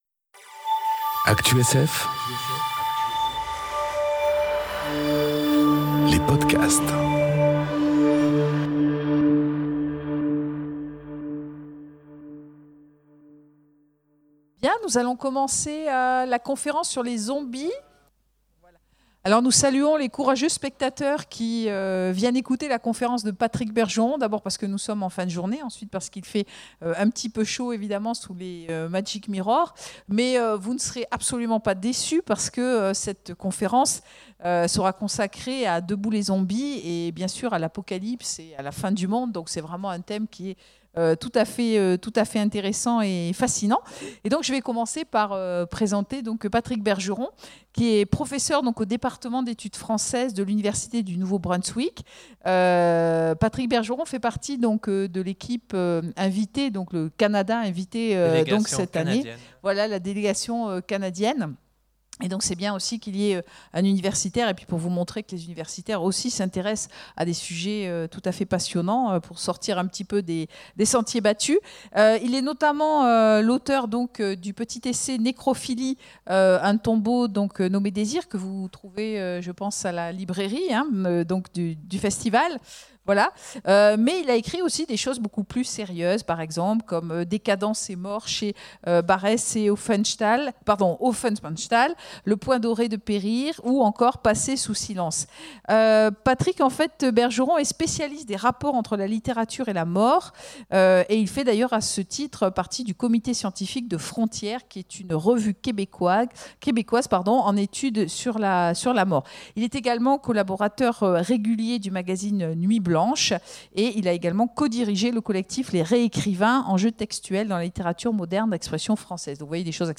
Conférence Debout les zombies... Apocalypse(s) et imaginaires de la fin enregistrée aux Imaginales 2018